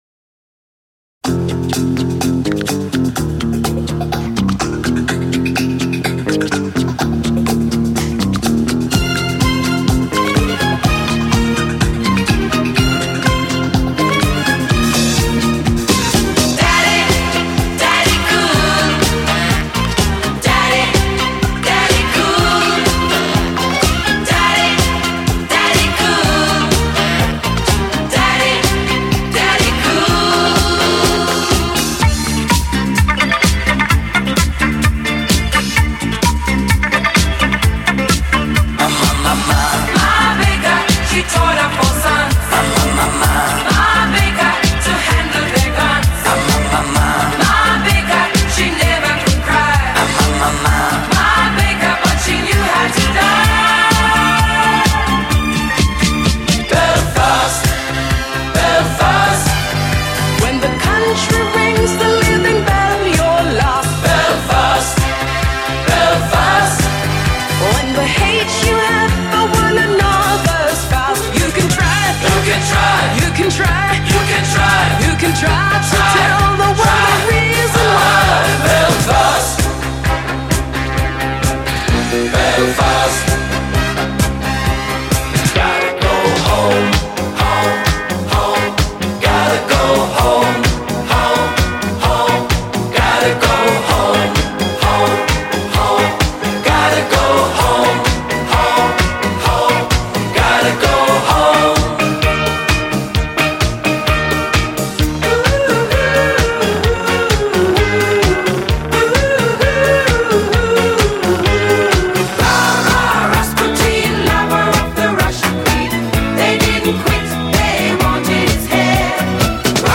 专辑风格：迪斯科